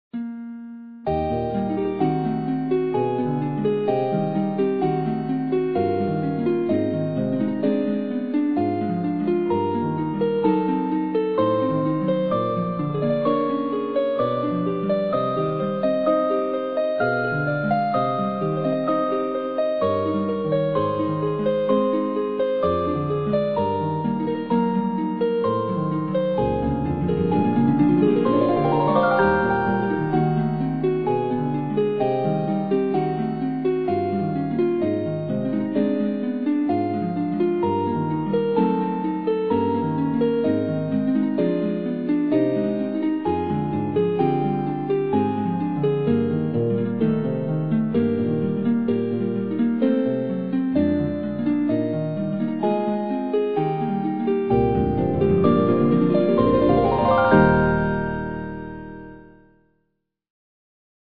The Pendulum, for harp quartet
In this short piece, four harps share the theme and its accompaniment in equal shares, making the harp quartet circulate and swing the melody from one instrument to another, as a pendulum would do.